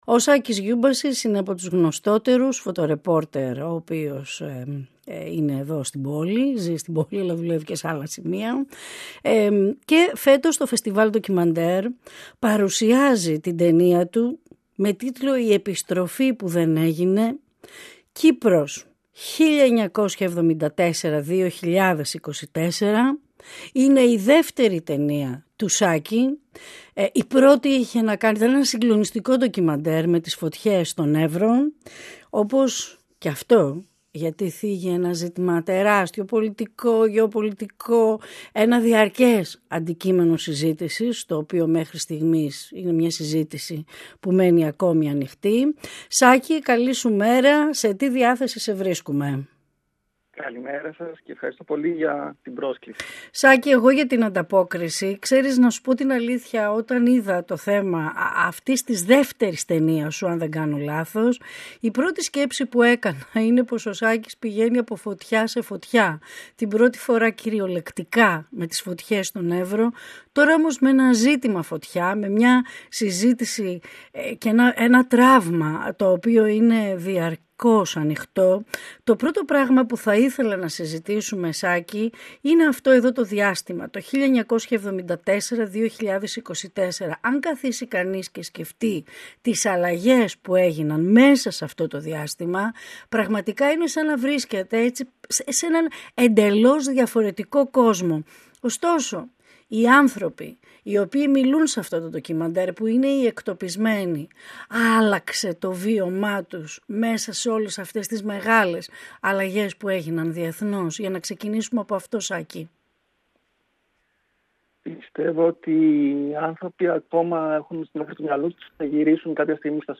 Κύπρος 1974-2024”. 958FM Ολιγη Κινηση του Δρομου και των Μαγαζιων Συνεντεύξεις ΕΡΤ3